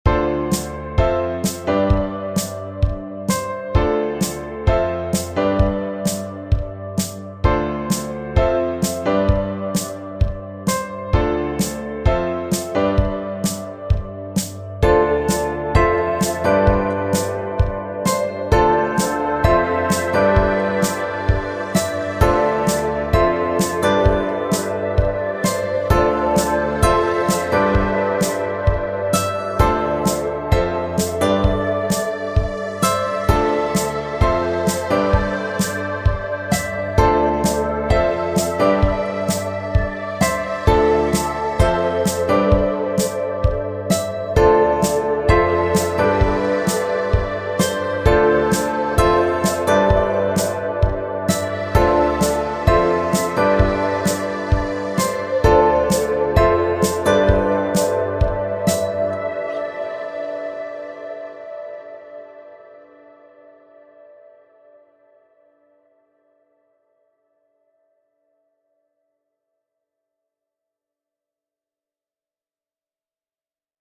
Romance – Free Stock Music